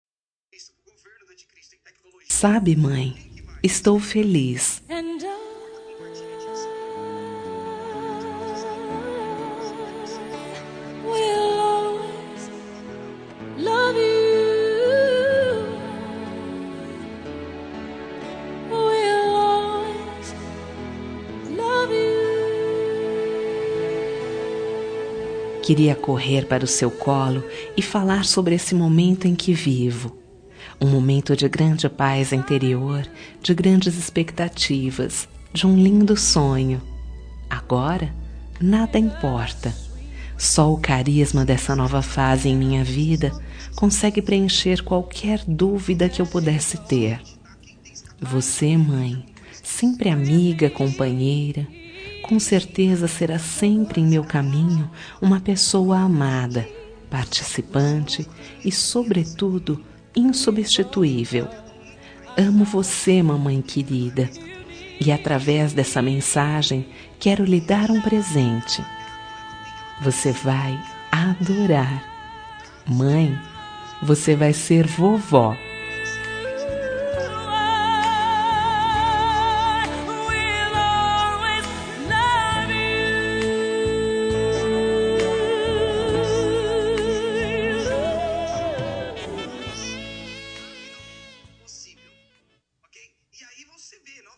Telemensagem para Gestante – Filha avisando a Mãe que vai ser Avó – Voz Feminina – Cód: 6629